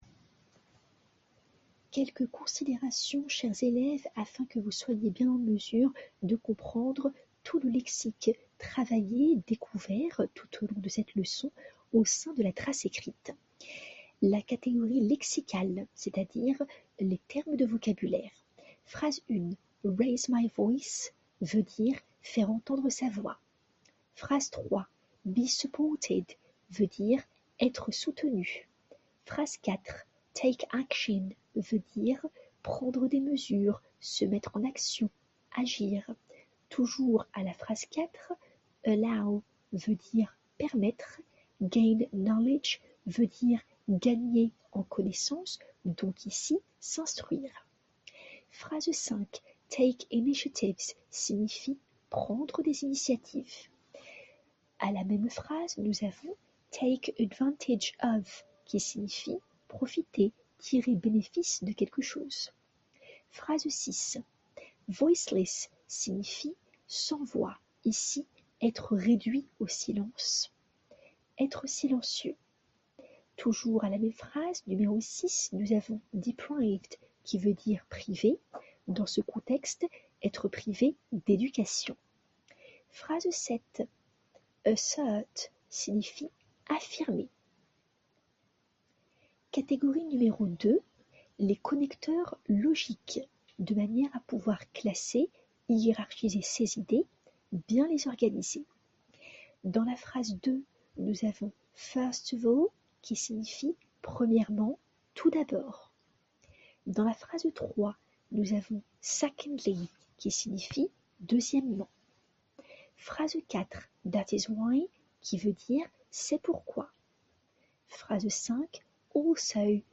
Audio 4 du professeur d'une durée de 04:16: